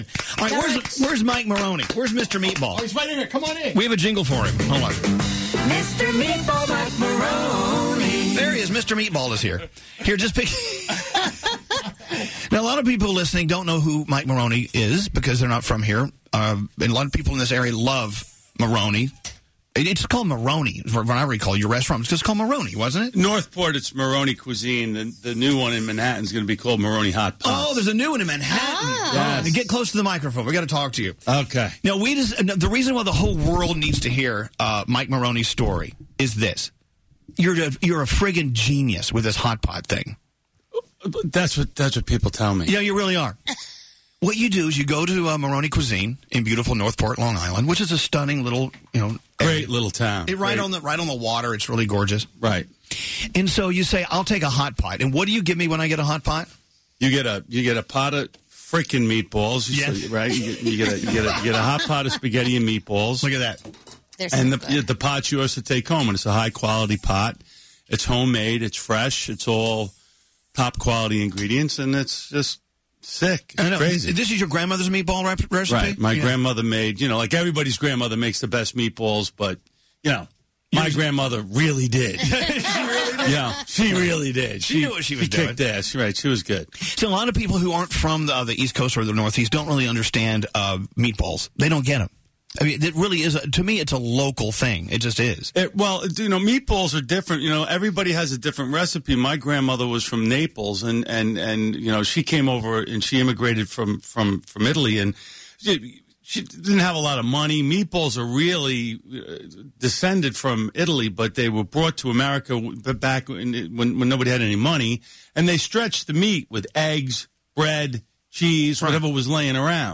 Z100’s Elvis Duran and the Morning Show/New York